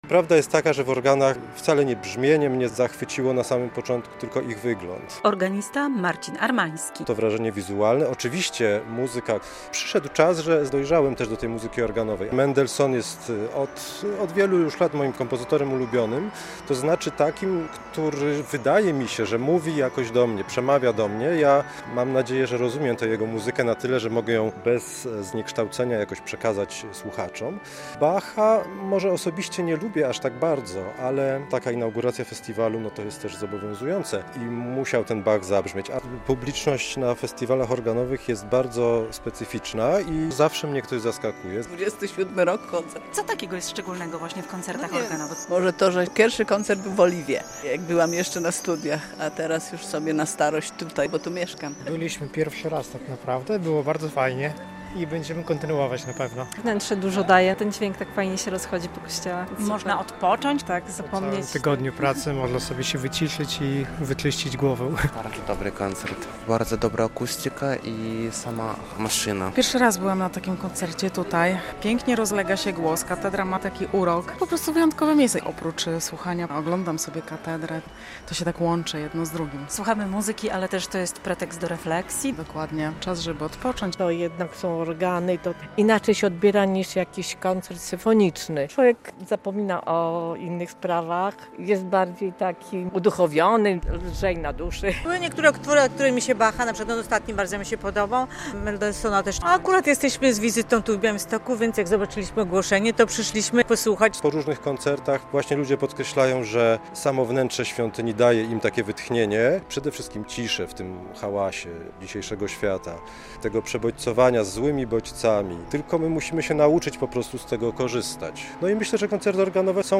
Od lat w białostockiej bazylice katedralnej w sezonie letnim odbywają się koncerty muzyczne.
Wszyscy: melomani, ale też przypadkowi przechodnie, turyści, mogą posłuchać muzyki klasycznej w interpretacji instrumentalistów z różnych stron Polski, a w tym roku będą też goście z USA i Danii.